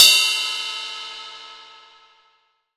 Index of /90_sSampleCDs/AKAI S6000 CD-ROM - Volume 3/Drum_Kit/ROCK_KIT1